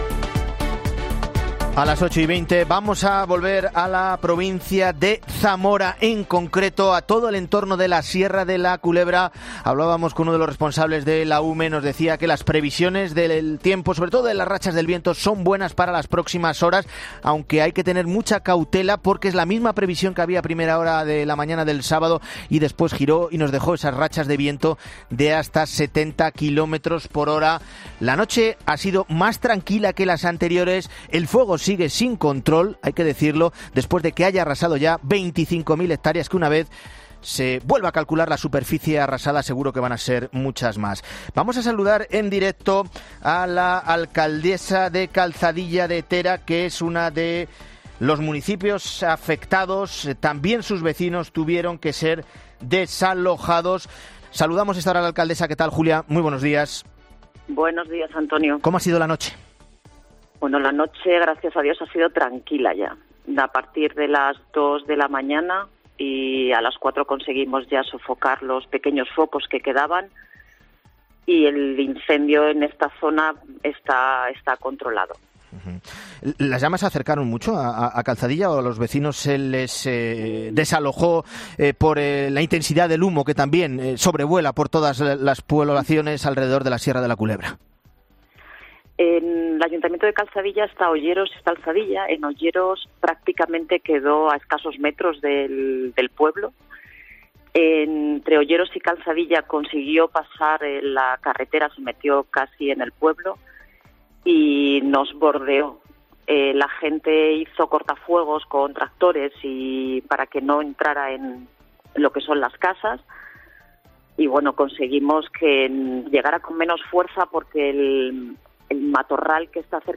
La alcaldesa de Calzadilla de Tera, Julia Celestina Fernández ha explicado en 'La Mañana de Fin de Semana' que "todos los vecinos pueden volver" a su municipio